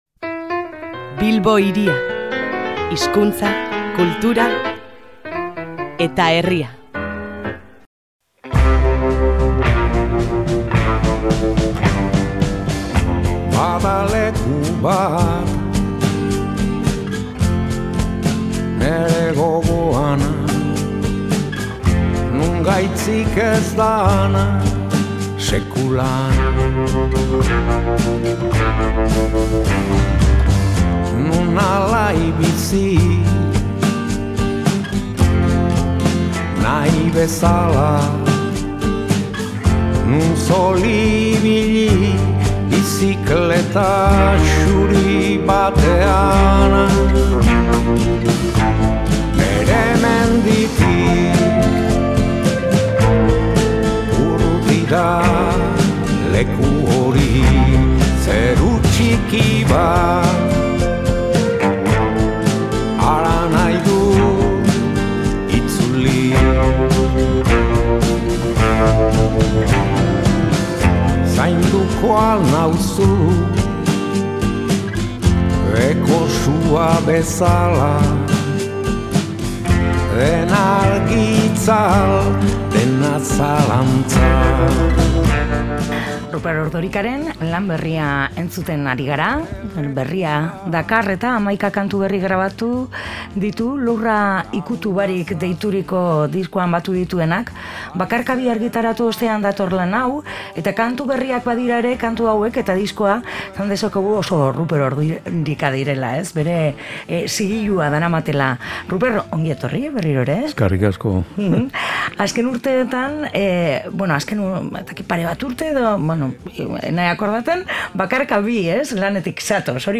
Ruper Ordorikaren bisita jaso dugu Bilbo Hiria irratian, bere disko berriaren karietara. 11 kantu berri grabatu eta bildu ditu Lurra ikutu barik deituriko bilduman.